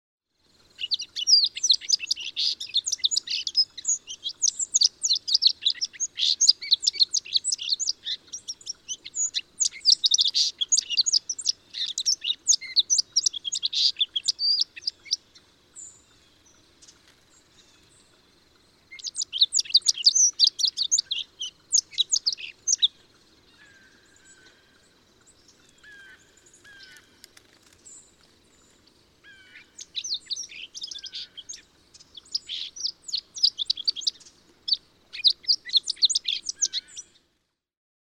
Song sparrow
♫170. Plastic song from a four-month-old male.
Quabbin Park, Ware, Massachusetts.
170_Song_Sparrow.mp3